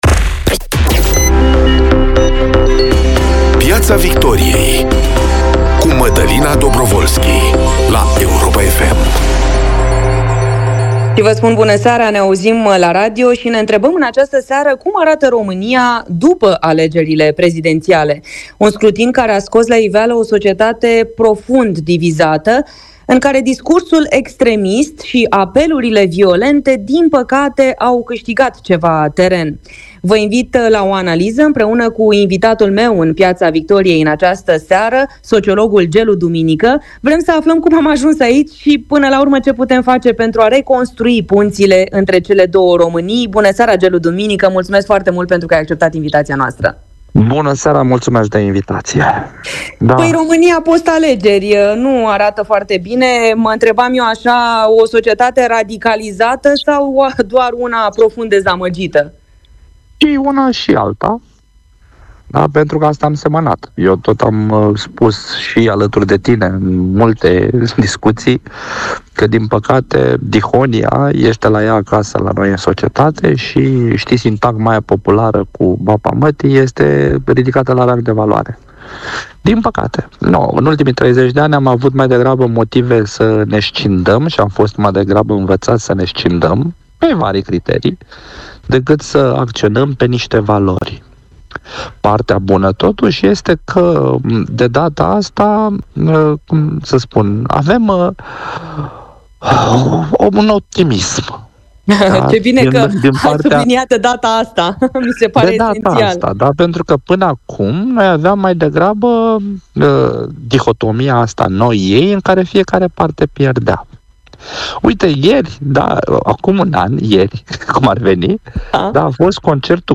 de la 18.15. Un duplex București Washington despre importanța poziției României, în vreme de război.